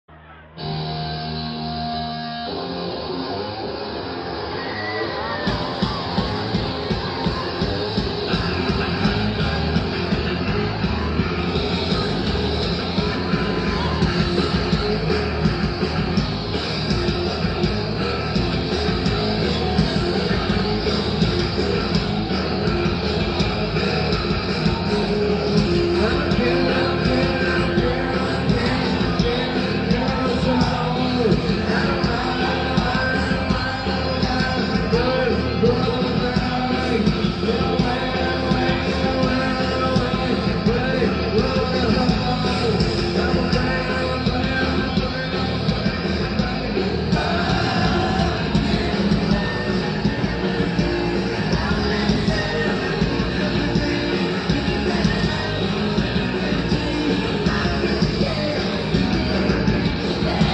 Рок
был записан на хорошей студии и за хорошие деньги